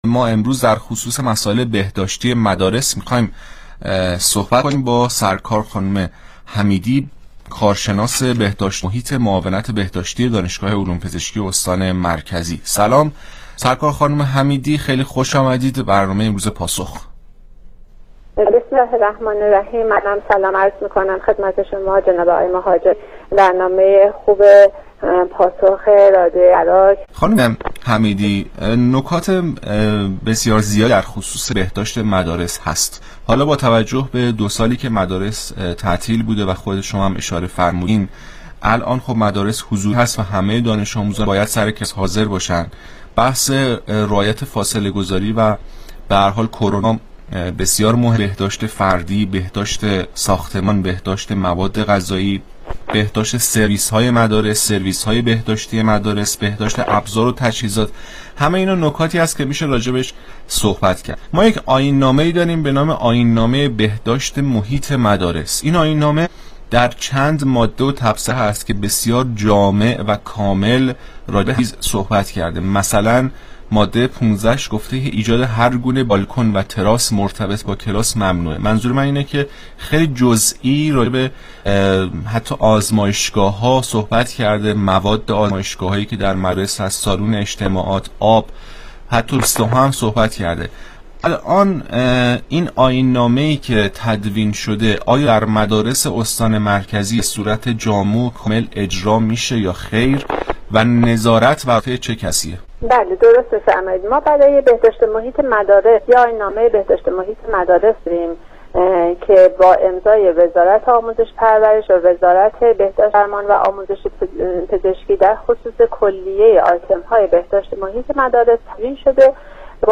برنامه رادیویی پاسخ گفتگوی تلفنی